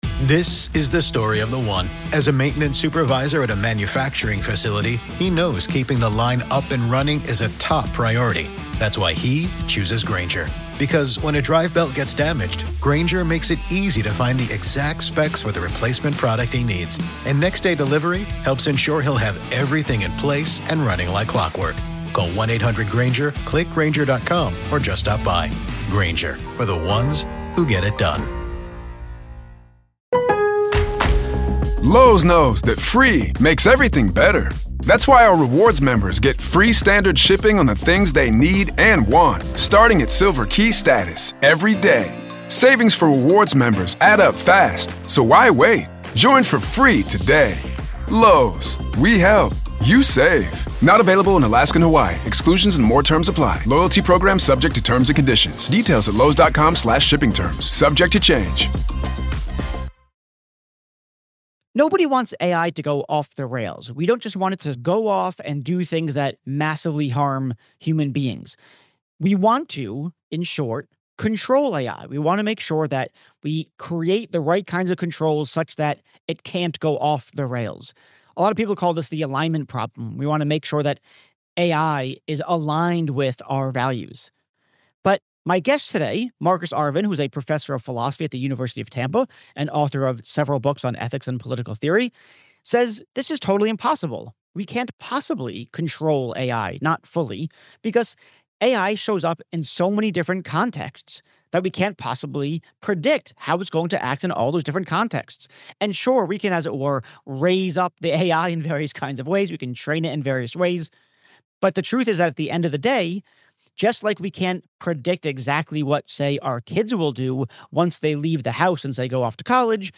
You might just leave this conversation wondering if giving more and more capabilities to AI is a huge, potentially life-threatening mistake.